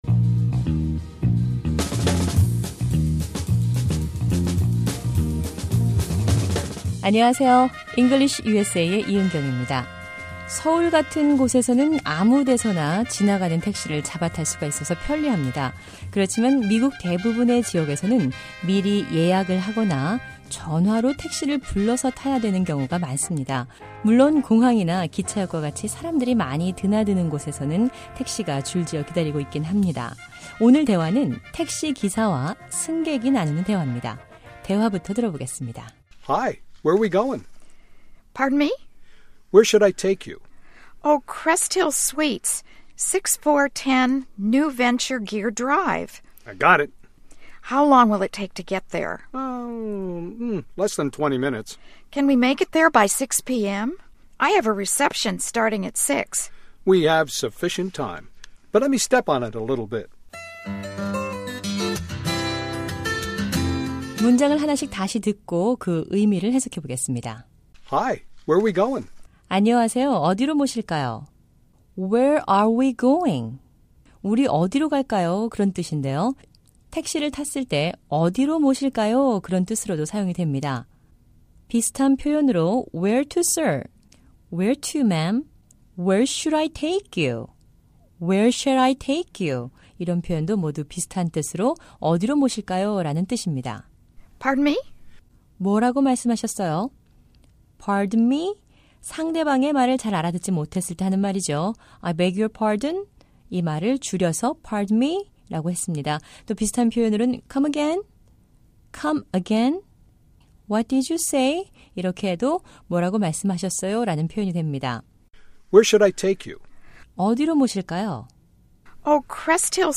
English USA는 일상 생활에서 자주 사용하는 영어 표현을 배워보는 시간입니다. 오늘 대화는 택시 기사와 승객이 나누는 대화입니다.